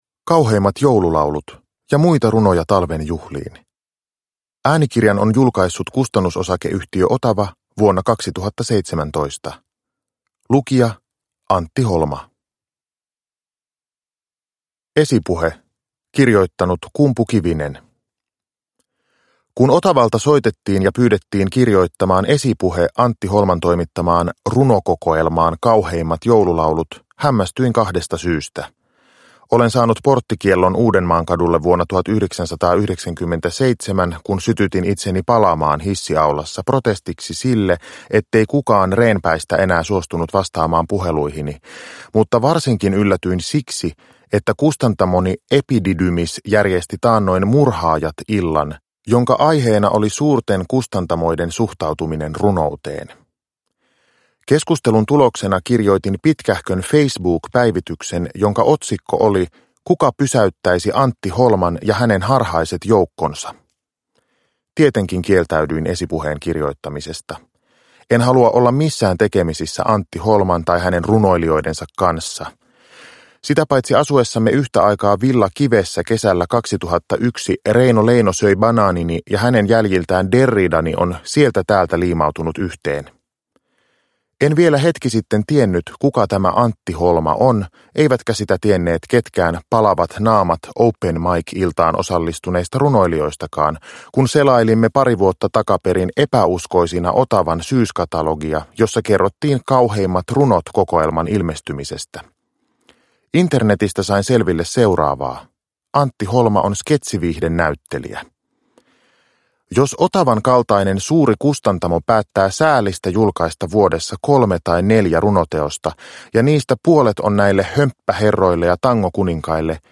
Uppläsare: Antti Holma